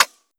countdown-tick.wav